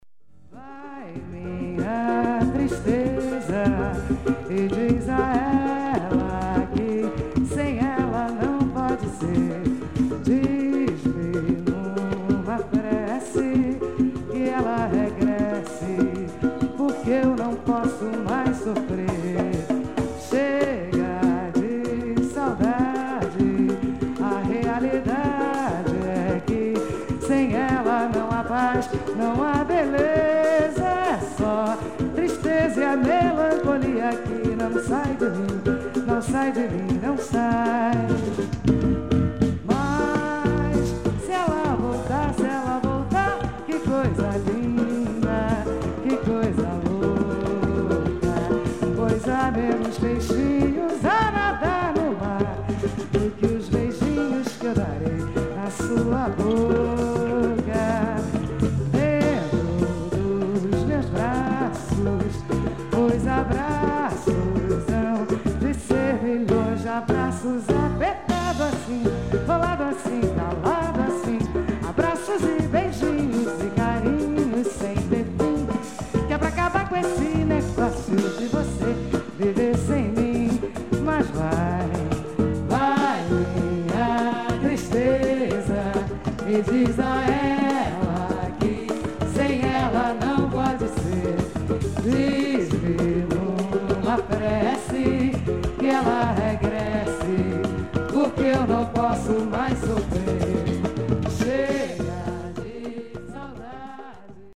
キューバ屈指のリゾート地”バラデロ”で１９８８年に開催されたフェスティバル